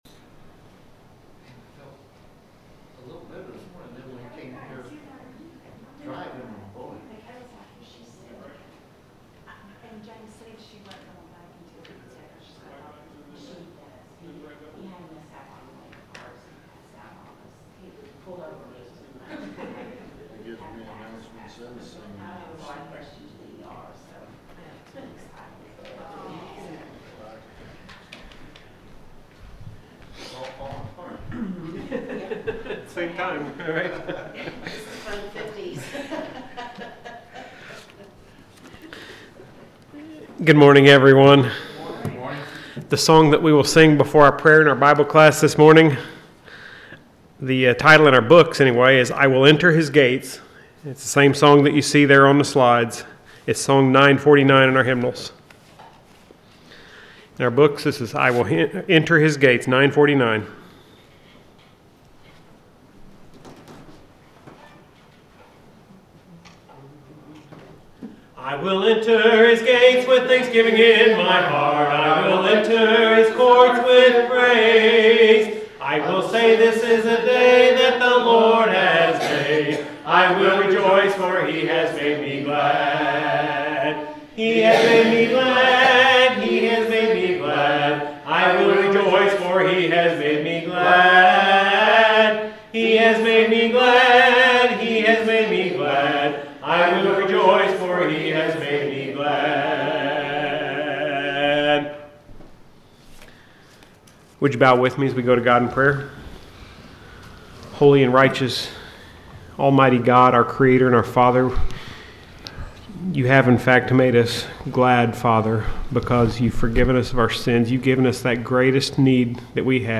The sermon is from our live stream on 1/4/2026